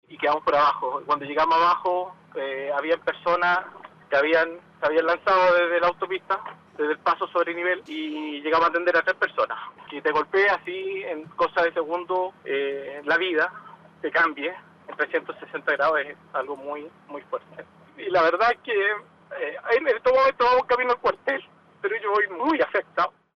Uno de ellos entregó su testimonio y conmocionado a las lágrimas, relató que “cuando llegamos abajo había personas que se habían lanzado de la autopista”.